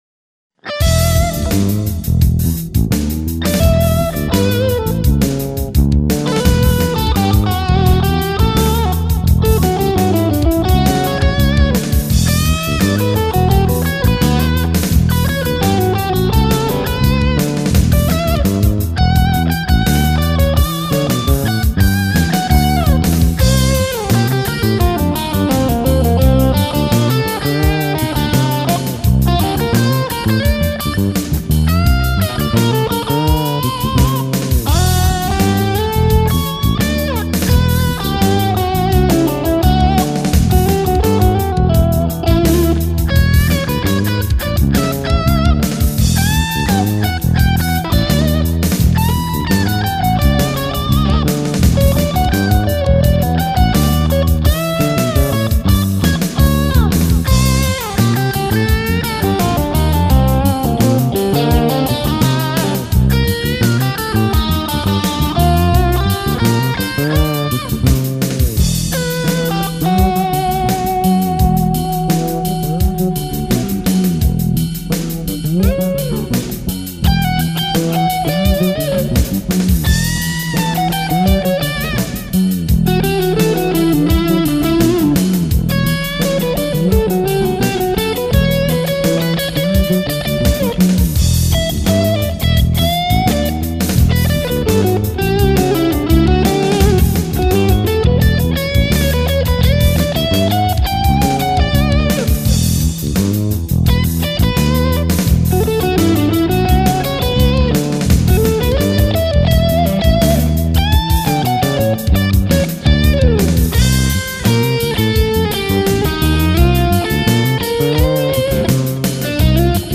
dorisch, lokrisch, mixo, moll, .... ich habe das alles mal in einen Topf geworfen, ordentlich durchgerührt, dann die einzelnen Noten willkürlich auf dem modalen Teppich verteilt und das ist dabei rausgekommen: